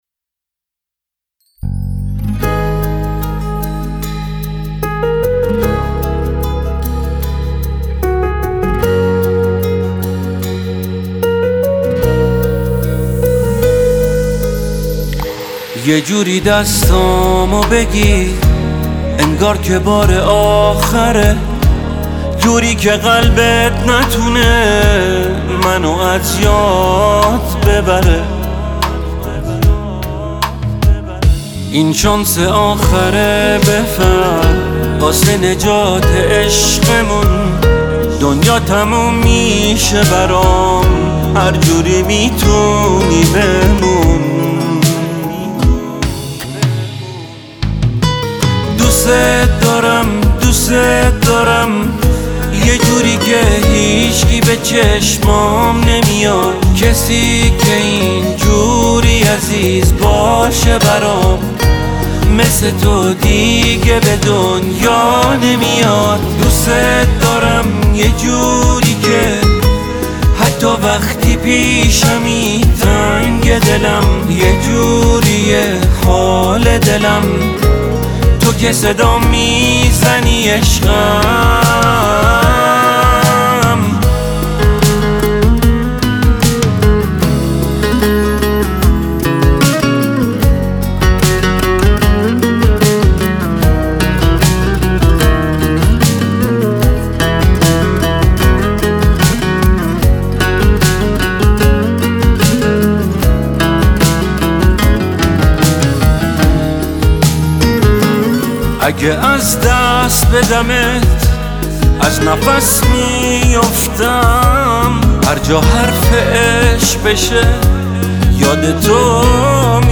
عاشقانه